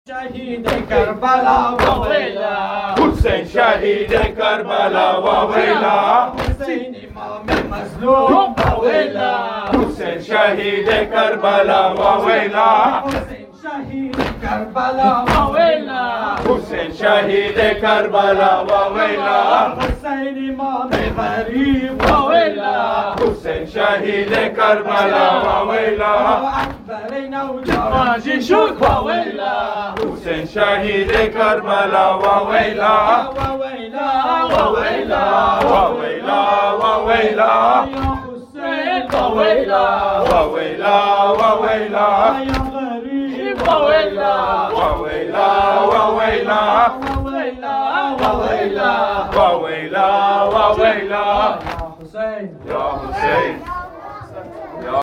Ending Matams